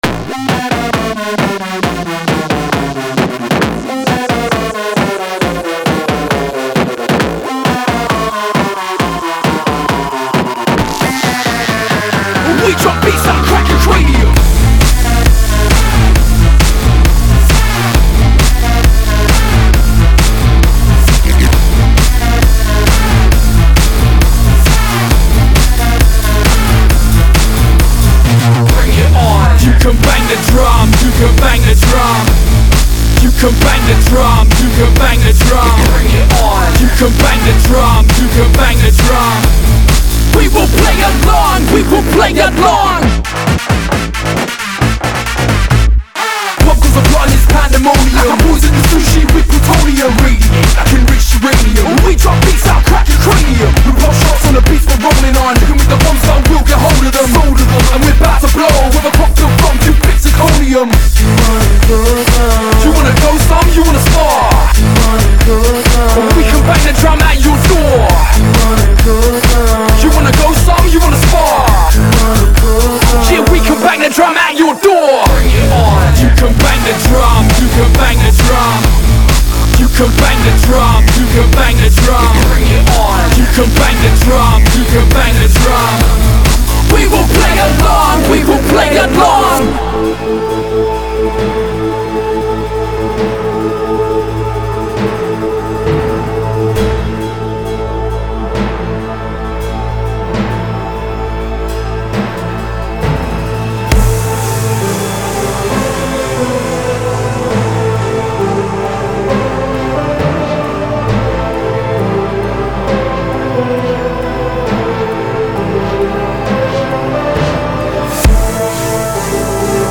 Стиль музыки: D'n'B